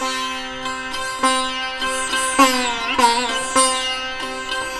100-SITAR5-L.wav